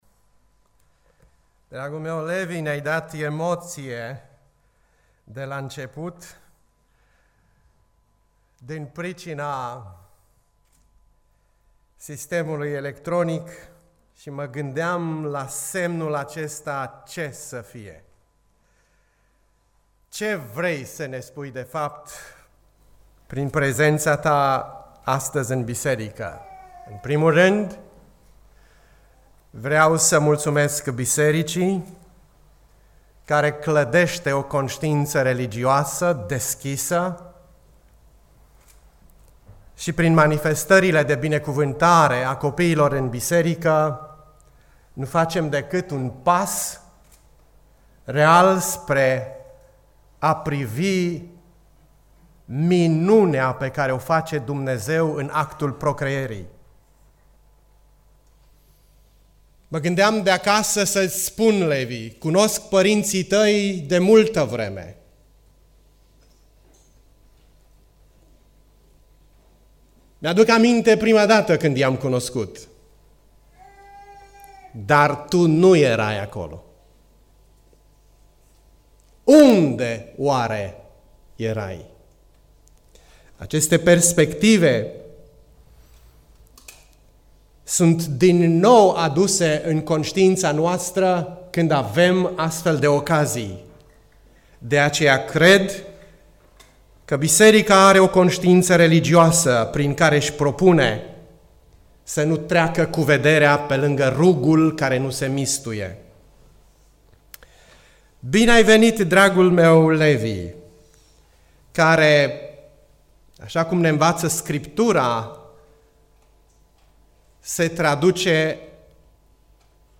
Predica Aplicatie 2 Timotei 3